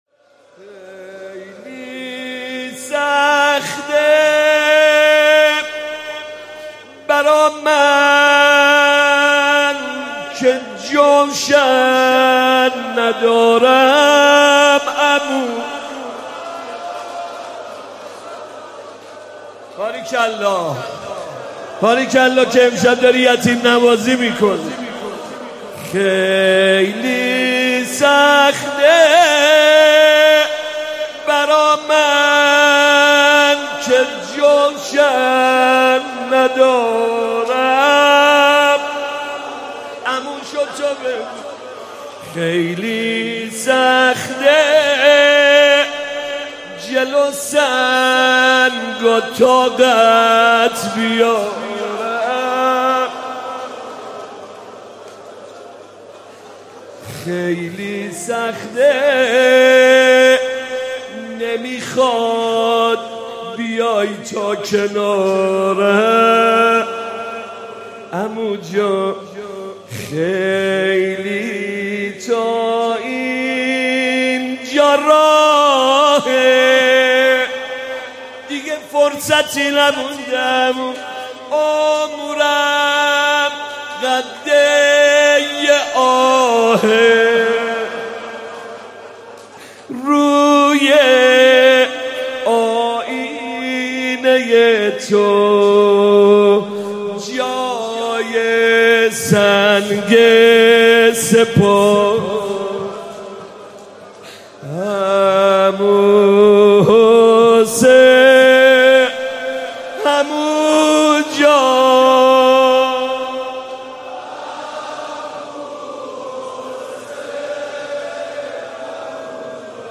مداحی جدید حاج محمدرضا طاهری شب ششم محرم97 هیئت مکتب الزهرا